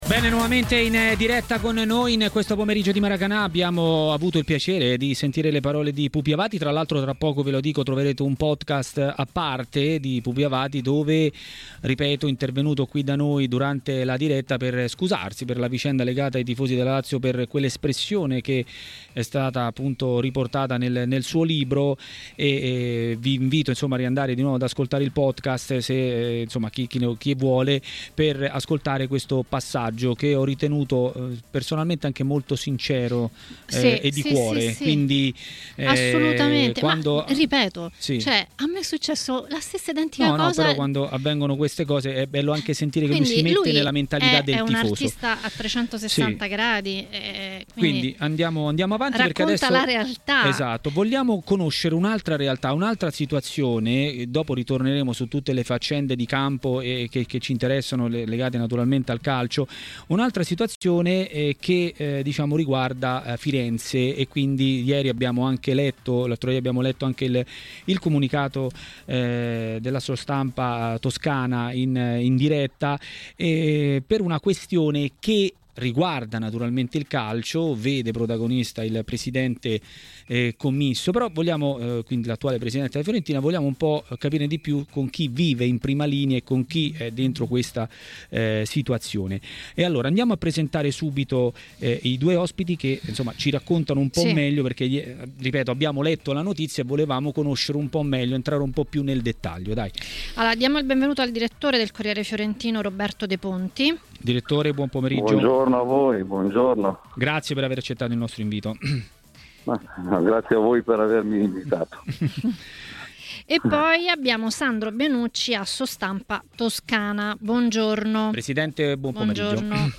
Maracanà , trasmissione di Tmw Radio